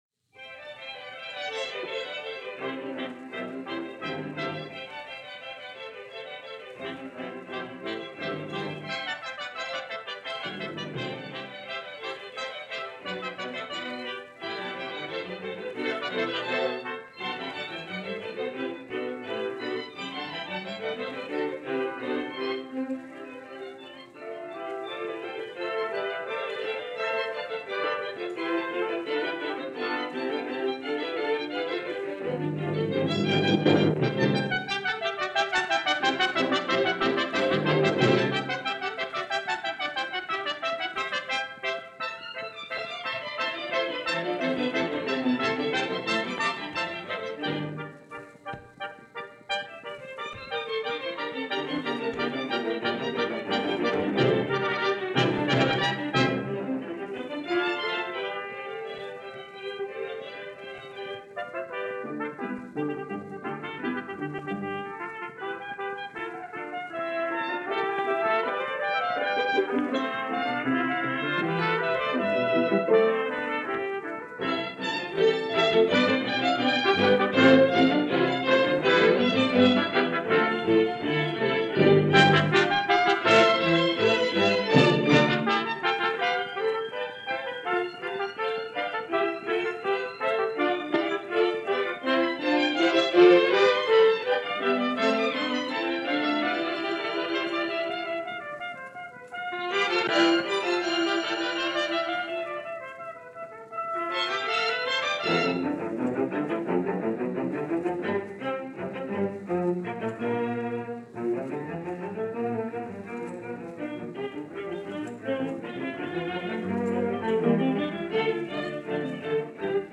cond. 1953 broadcast